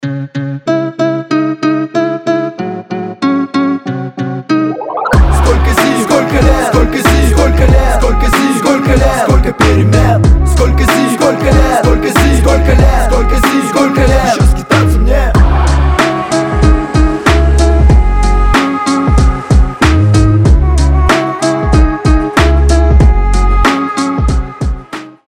Хип-хоп
качающие